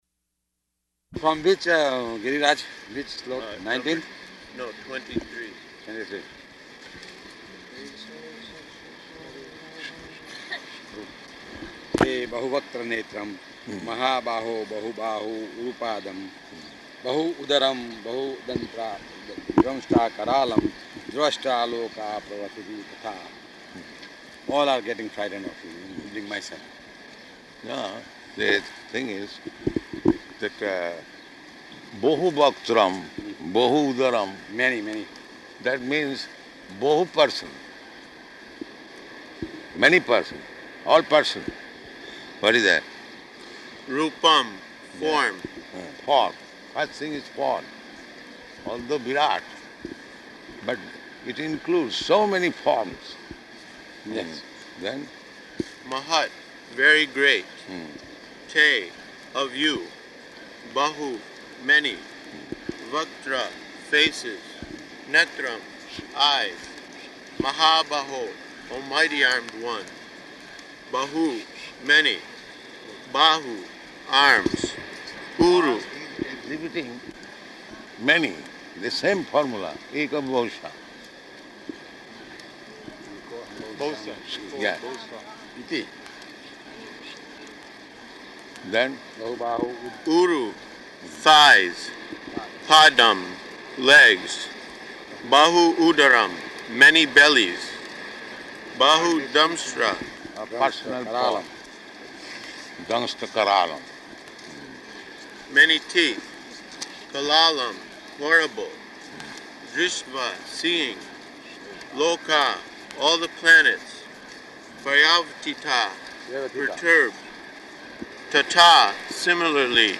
-- Type: Walk Dated: April 5th 1974 Location: Bombay Audio file